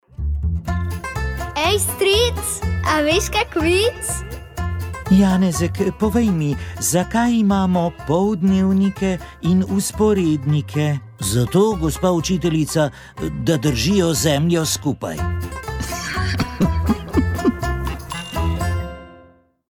Srčno popuščanje je bolezen sodobnega časa, število bolnikov se zelo hitro povečuje, že sedaj z njo živi kar 30.000 Slovenk oziroma Slovencev. Znanost prav tako dela hitre korake v razvoju zdravljenja, a uspešni bodo, če bodo bolniki sami poznali dejavnike tveganja. Naš gost je specialist interne medicine